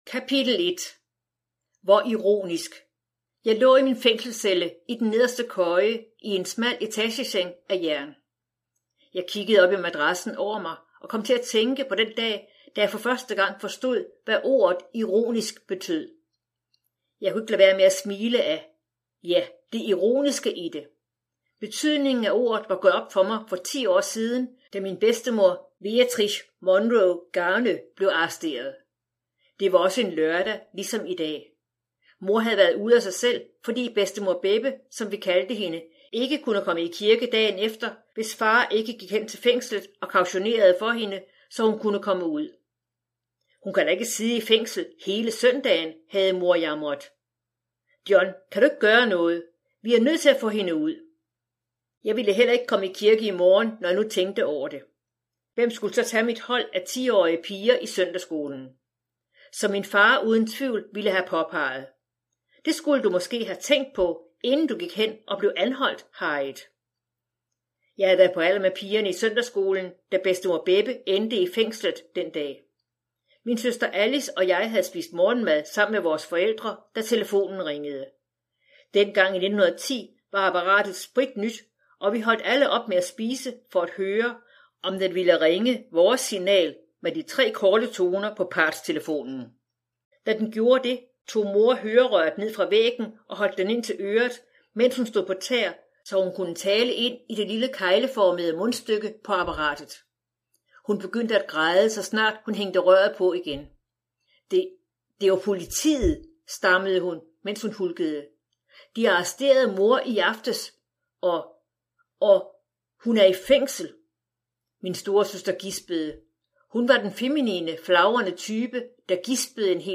Hør et uddrag af Selv når bølgerne bruser Selv når bølgerne bruser Format MP3 Forfatter Lynn Austin Bog Lydbog E-bog 249,95 kr.